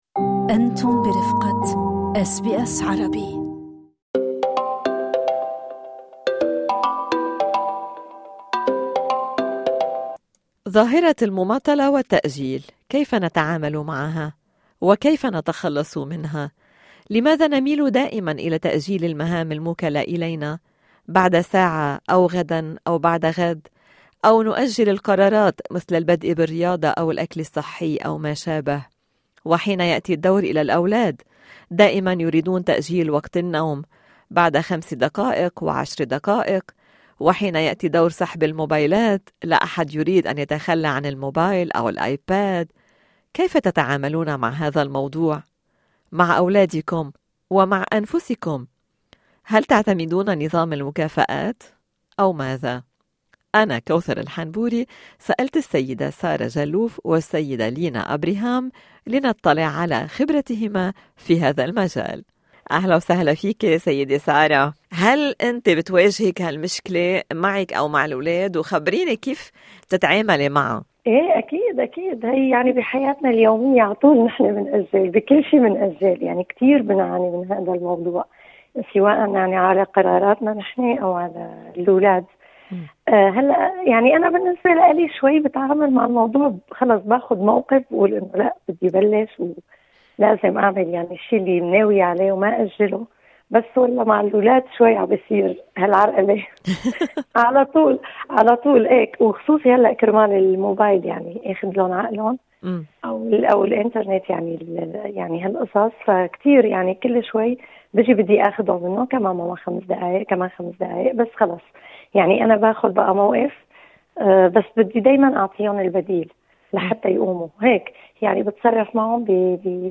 ناقشنا الموضوع مع سيدتين من سيدني